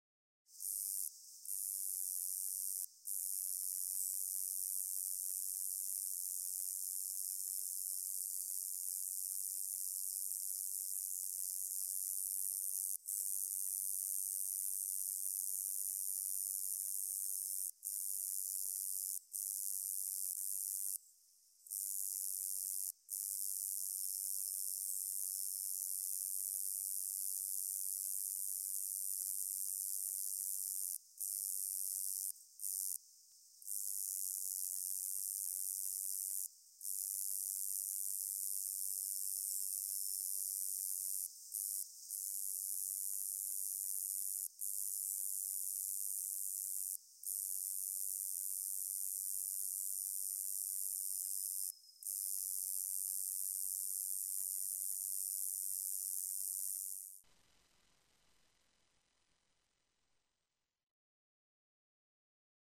Cikadegræshoppe (Roeseliana roeselii)
Lyt til cikadegræshoppen.
cikadegræshoppe.mp3